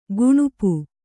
♪ guṇupu